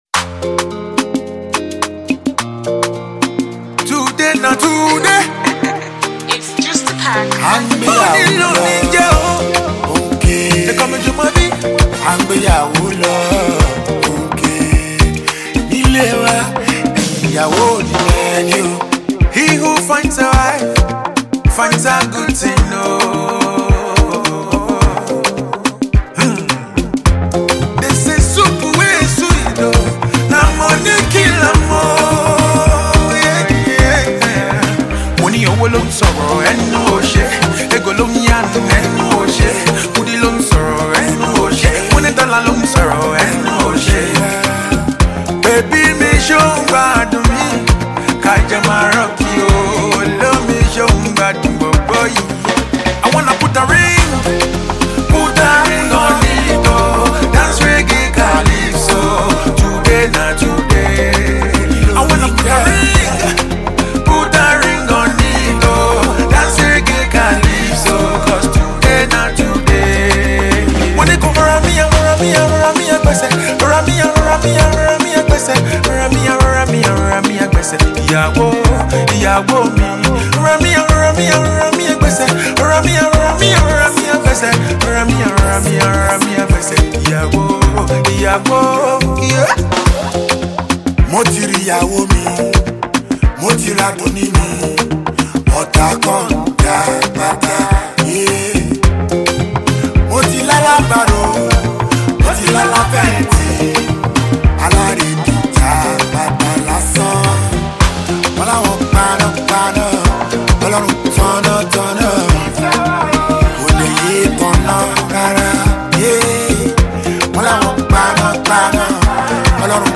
a melodious, danceable and refreshing love rendition